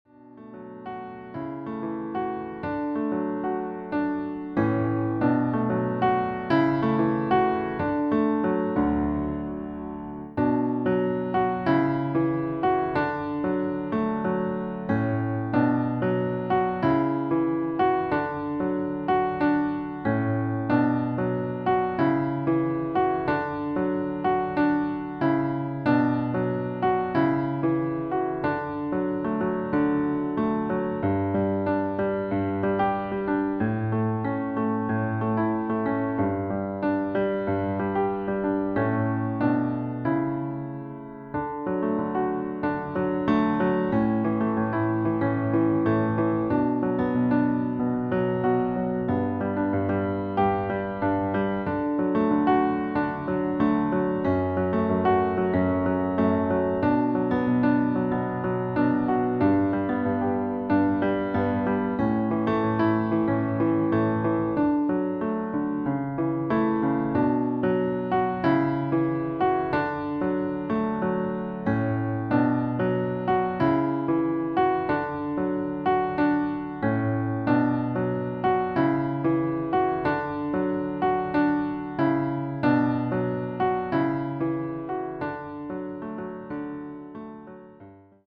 • Das Instrumental beinhaltet NICHT die Leadstimme
Klavier / Piano